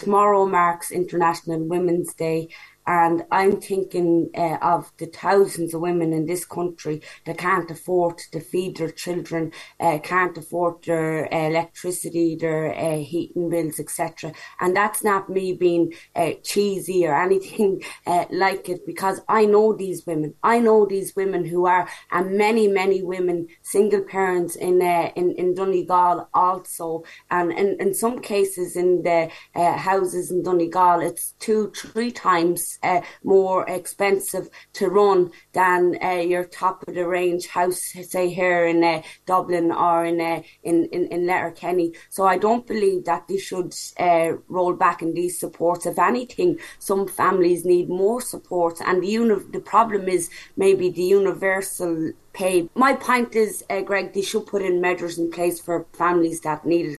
On this morning’s Nine til Noon Show